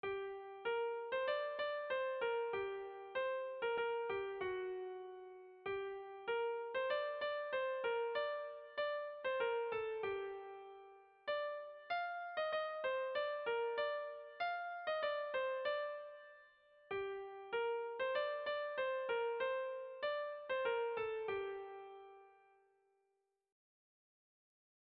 Betsoa guk egokitu diogu doinuari. 123 doinu datoz Auspoa liburuan zenbakiz ordenaturik, doinu soilak izenbururik eta bertsorik gabe.
Zortziko txikia (hg) / Lau puntuko txikia (ip)
A1A2BA2